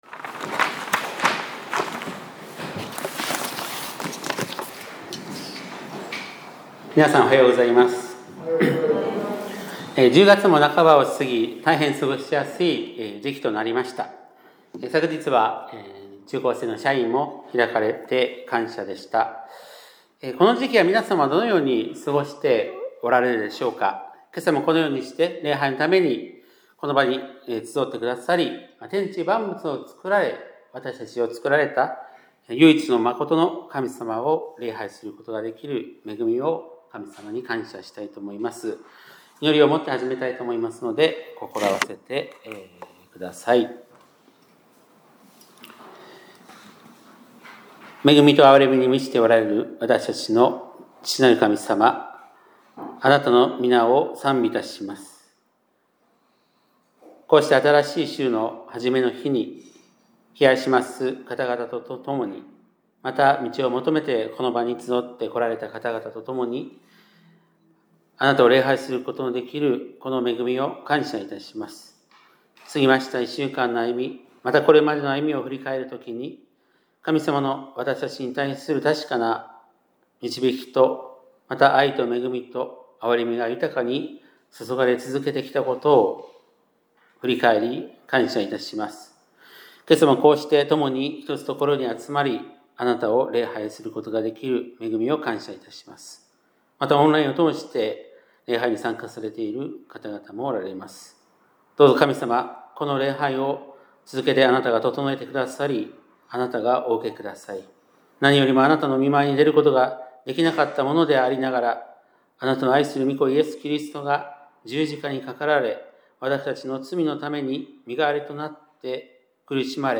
2025年10月19日（日）礼拝メッセージ